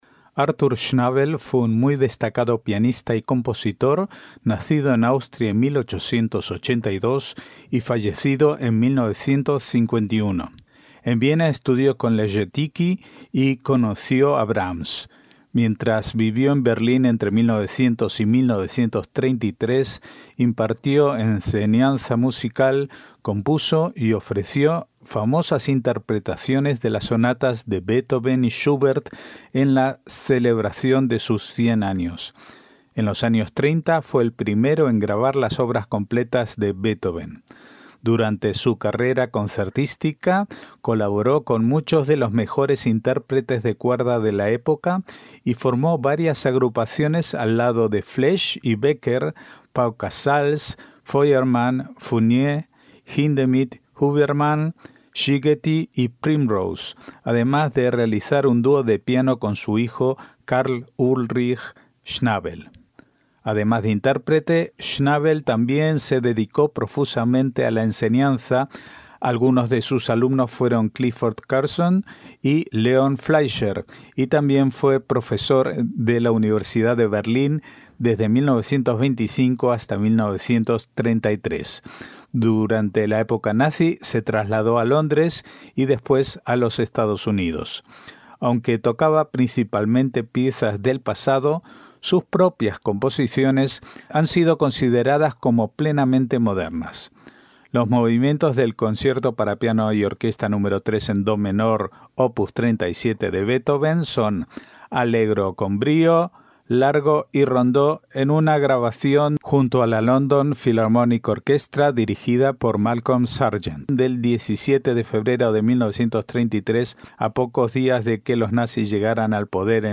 MÚSICA CLÁSICA - Artur Schnabel fue un muy destacado pianista y compositor nacido en Austria en 1882 y fallecido en 1951.